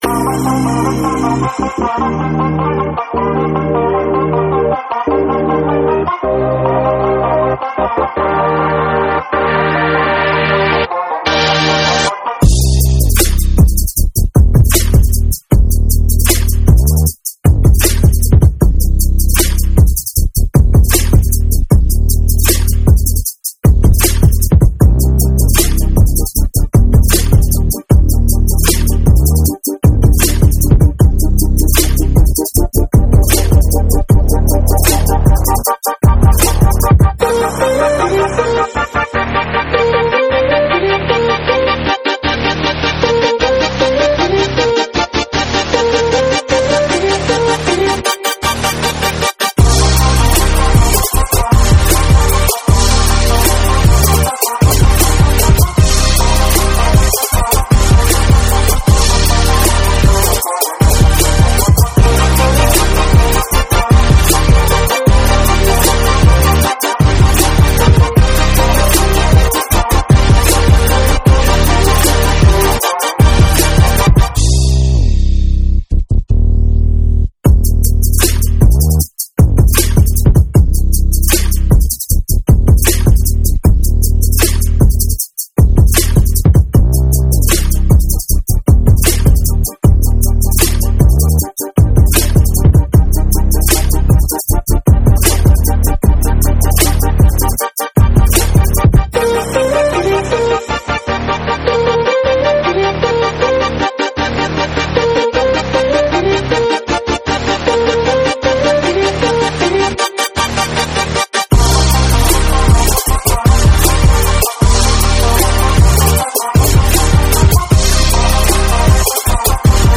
Pop, Pop Rock